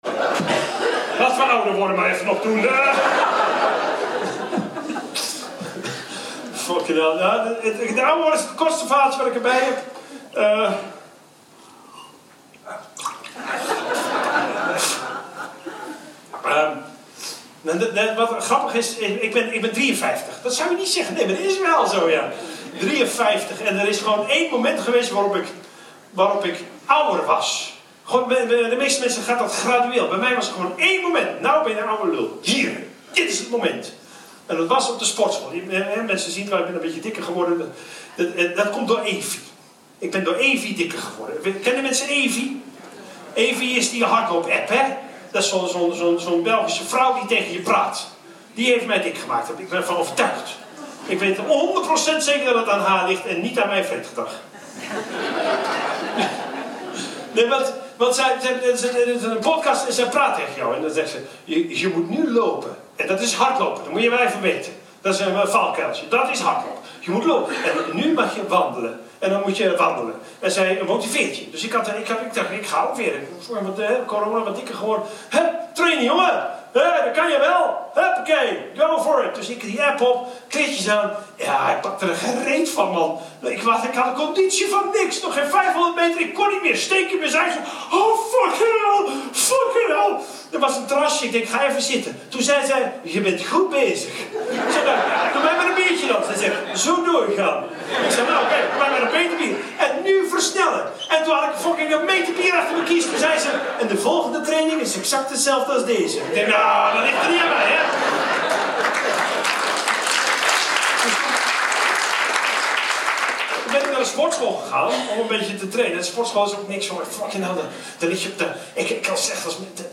luisterboek
ThemaComedy en stand-up